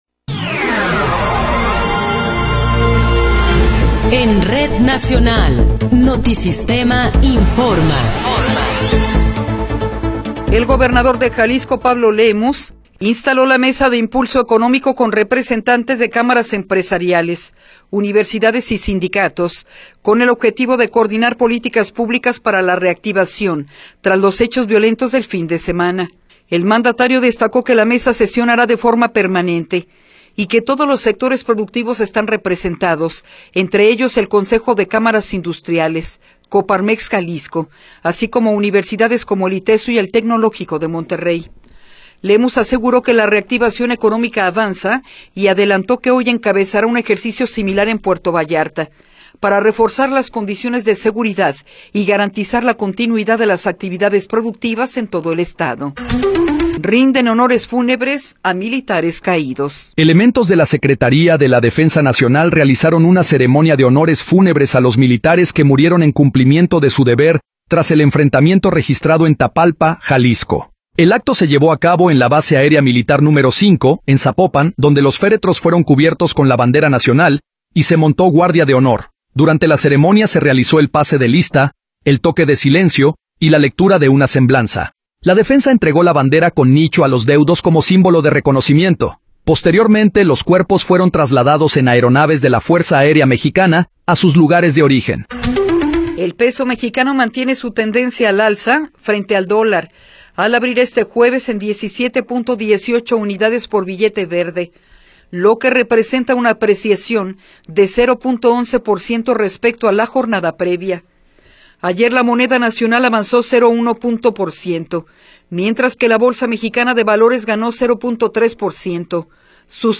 Noticiero 10 hrs. – 26 de Febrero de 2026
Resumen informativo Notisistema, la mejor y más completa información cada hora en la hora.